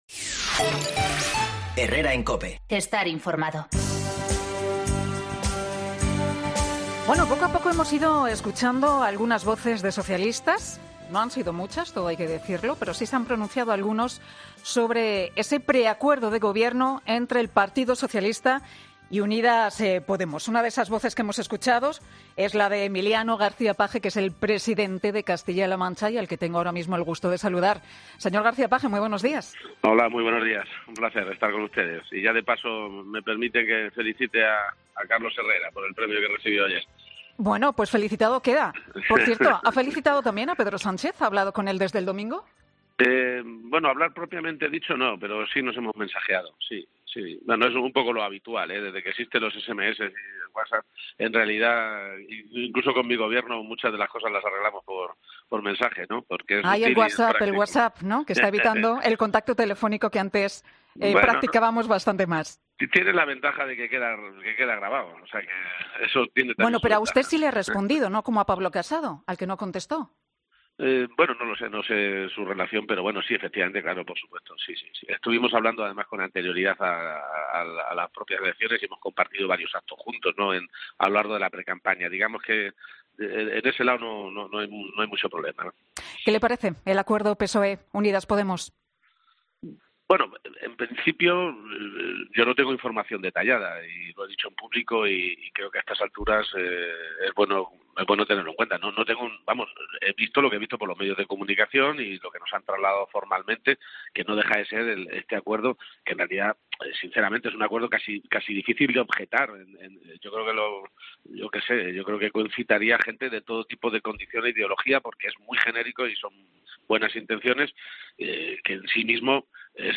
En una entrevista este viernes en 'Herrera en COPE', Page sí ha reconocido que Podemos también le quitó el sueño cuando gobernaban conjuntamente en Castilla-La Mancha.